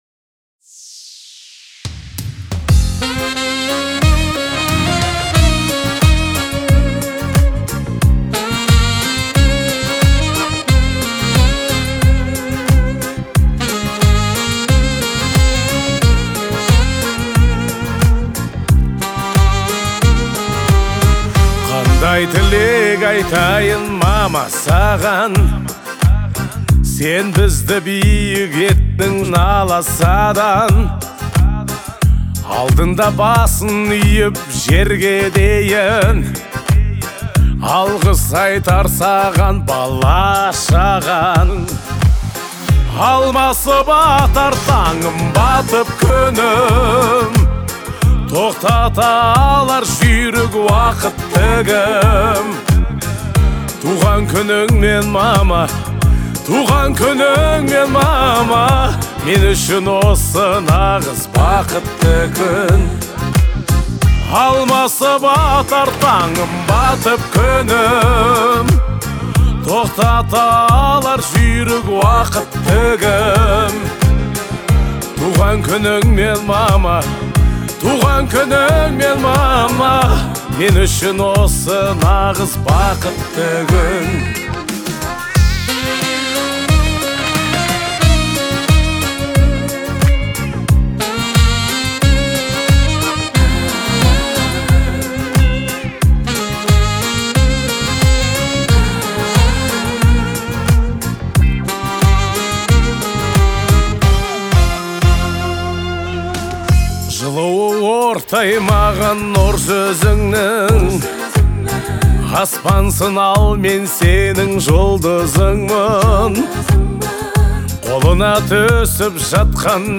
это трогательная и эмоциональная песня
относящаяся к жанру поп.